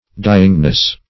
Search Result for " dyingness" : The Collaborative International Dictionary of English v.0.48: Dyingness \Dy"ing*ness\, n. The state of dying or the stimulation of such a state; extreme languor; languishment.